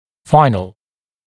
[‘faɪn(ə)l][‘файн(э)л]окончательный, конечный